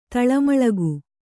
♪ taḷamaḷagu